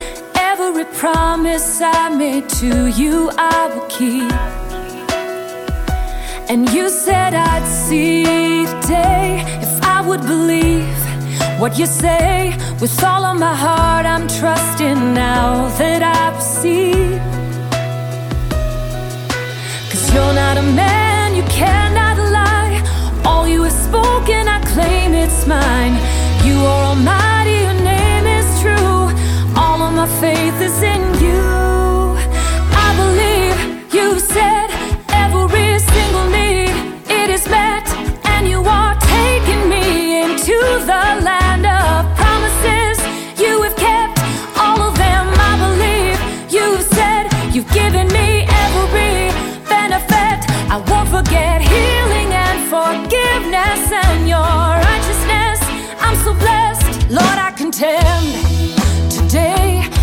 Gesang.
vereint viele musikalische Stile
multikulturelle Musik
Lobpreis